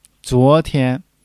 zuo2-tian1.mp3